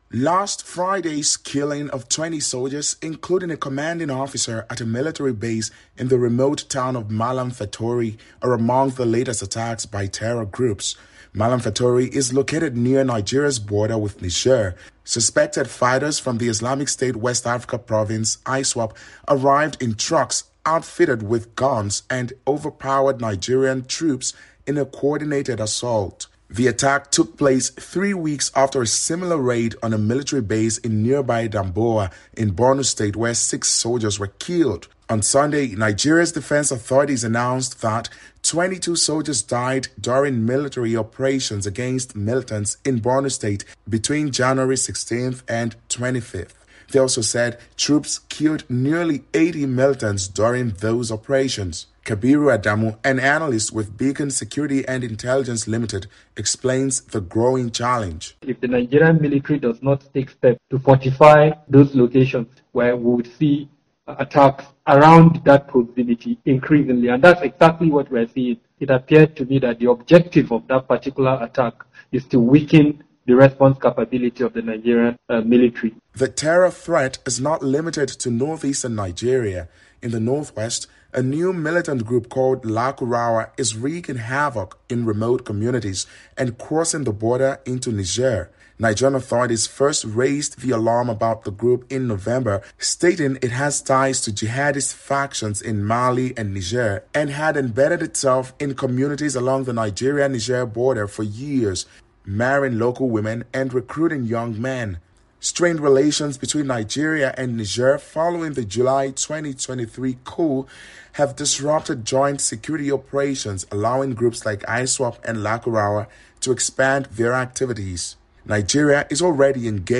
reports from Abuja.